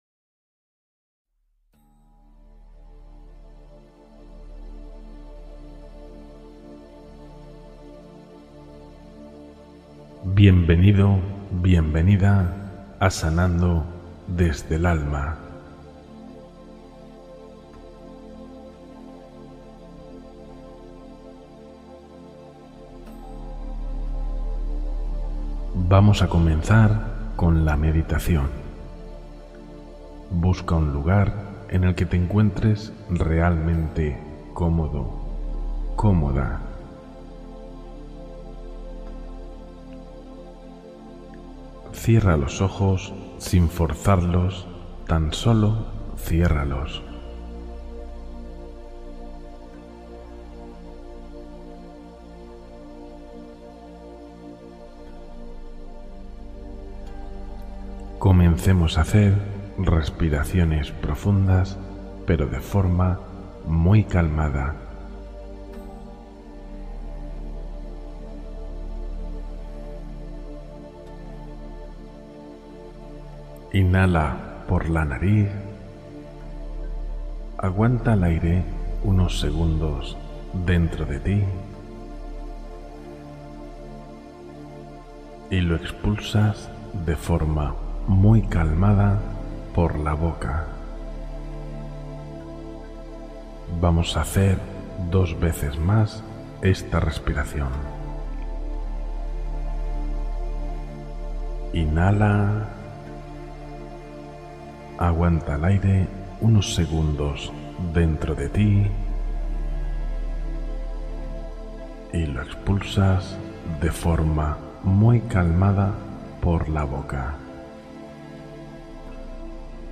Meditación guiada para conectar con memorias profundas del alma